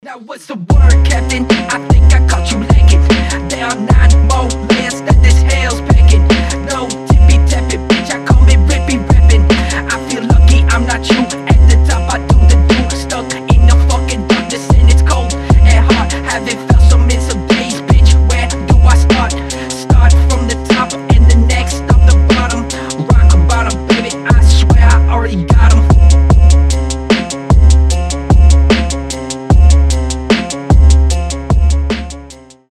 басы